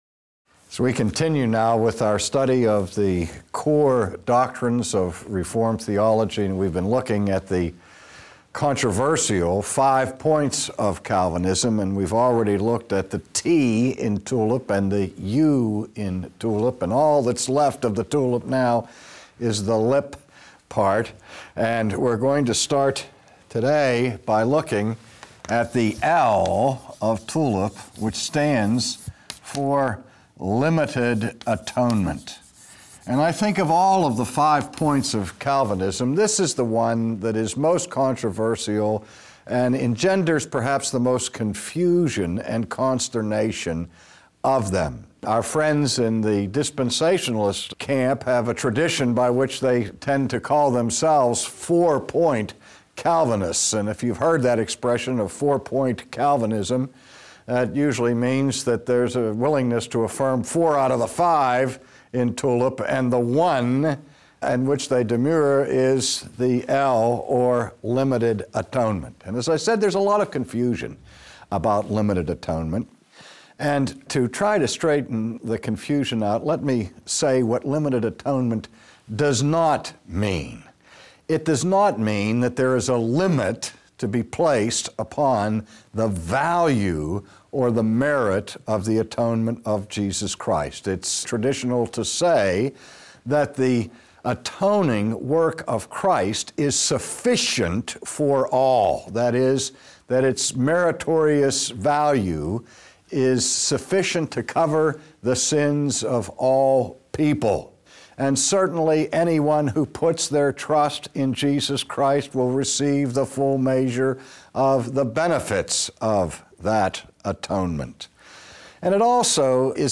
Lecture 10, Limited Atonement : The most common view of the atonement of Jesus is that Jesus died for everyone—that is, all people from all places in all times, every single human being that ever existed.